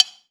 Clank6.wav